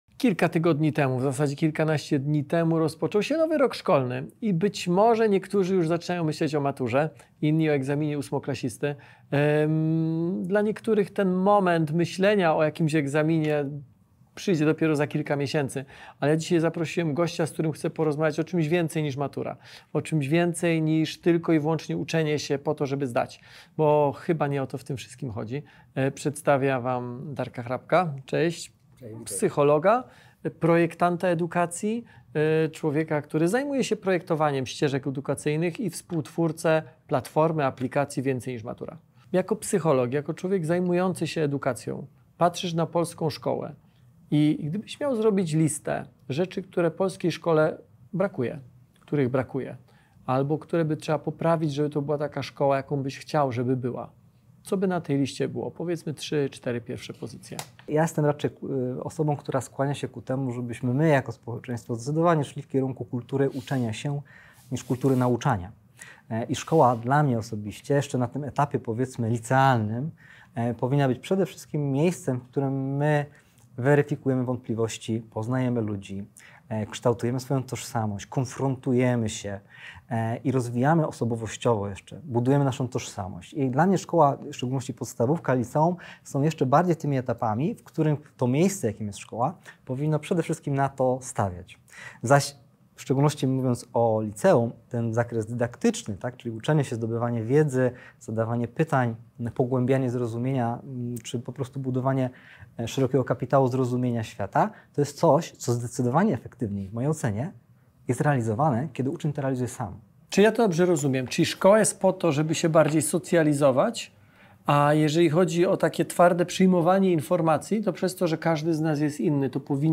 Więcej niż Matura: personalizacja nauki, odwrócona klasa i AI [rozmowa]